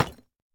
Minecraft Version Minecraft Version 25w18a Latest Release | Latest Snapshot 25w18a / assets / minecraft / sounds / block / deepslate_bricks / place6.ogg Compare With Compare With Latest Release | Latest Snapshot